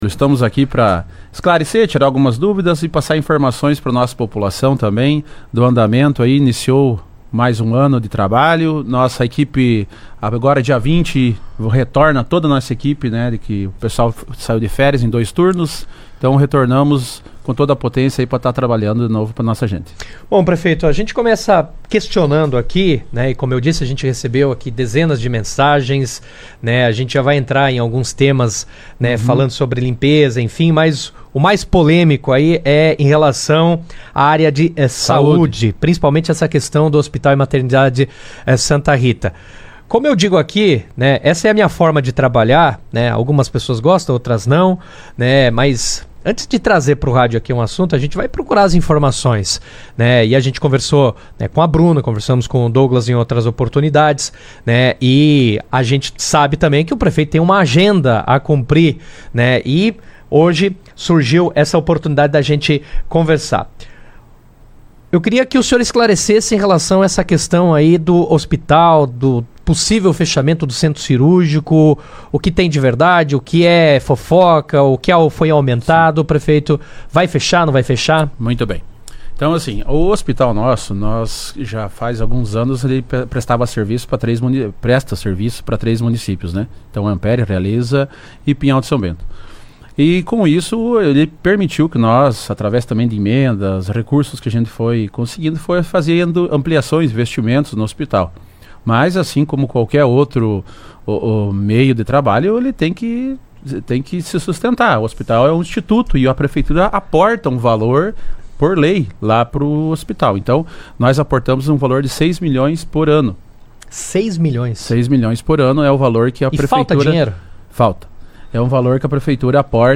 O prefeito de Ampére, Douglas Potrich, participou do Jornal RA 2ª Edição desta sexta-feira, 16, onde falou sobre a situação atual dos serviços públicos do município, com destaque para a área da saúde, além de apresentar projetos em andamento nas áreas de infraestrutura, meio ambiente, cultura e turismo.